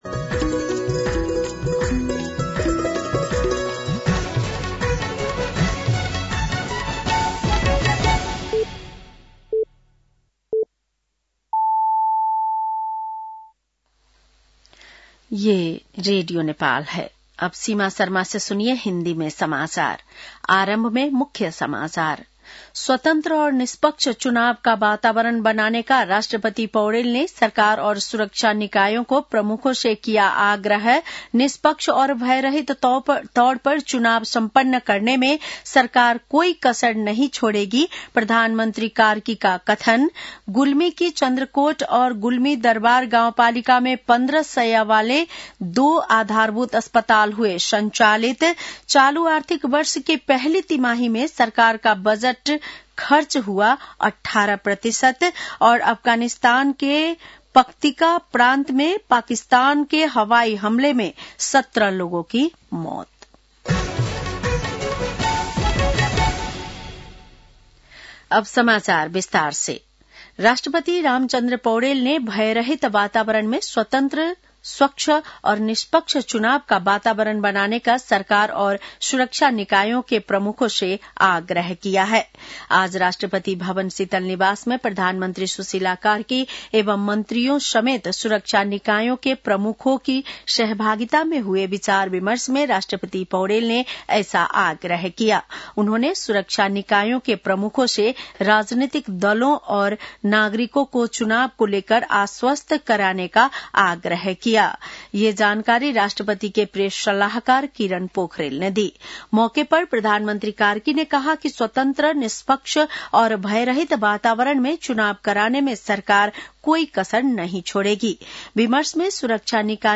बेलुकी १० बजेको हिन्दी समाचार : १ कार्तिक , २०८२